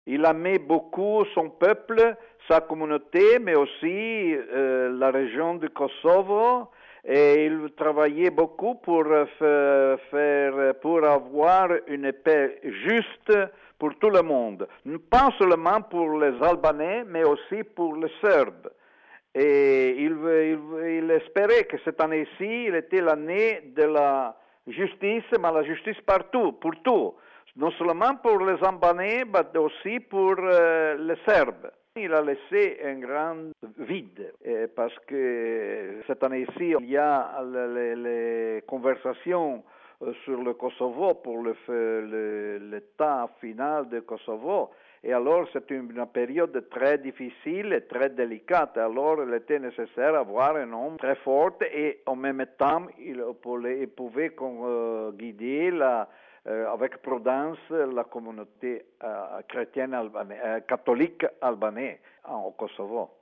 Le nonce apostolique à Belgrade, monseigneur Eugenio Sbarbaro, rend hommage à son ami, un homme de paix.